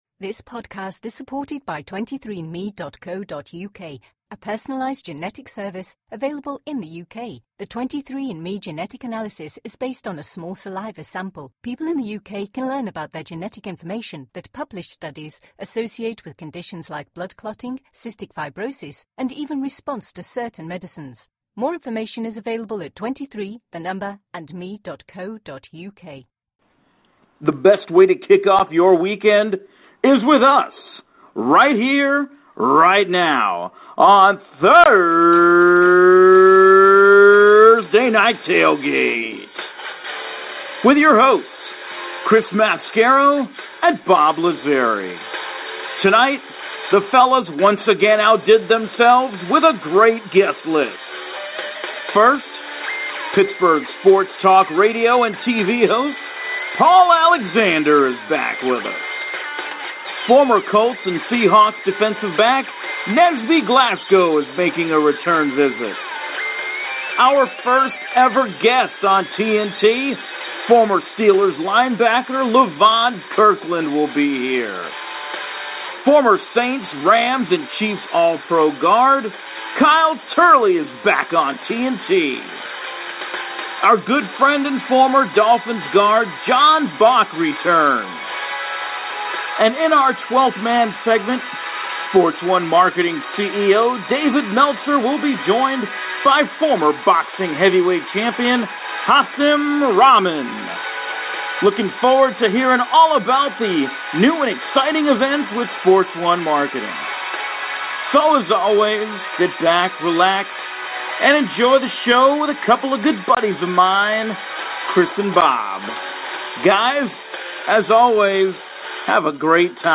as they talk with current and former players and coaches from around the NFL & CFL